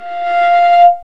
Index of /90_sSampleCDs/Roland L-CD702/VOL-1/STR_Viola Solo/STR_Vla Harmonx